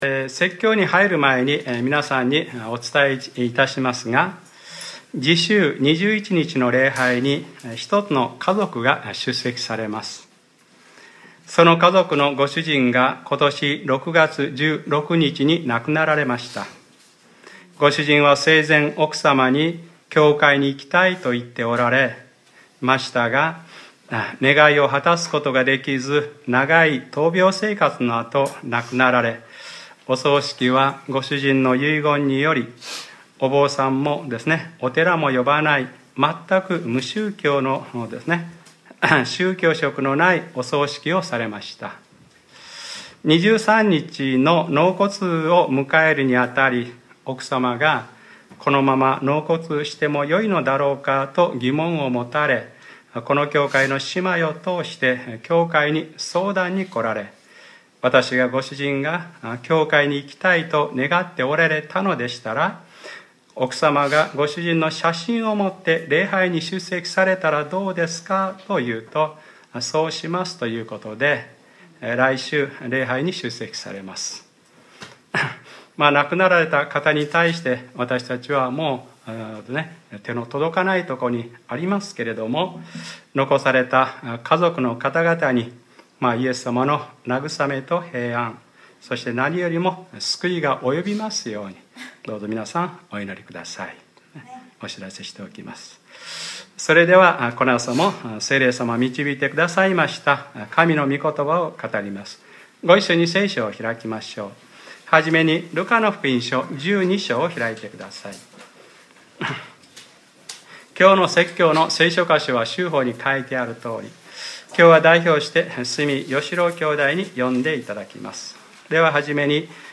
2014年9月14日（日）礼拝説教 『ルカｰ４５：神の前に富まない者は』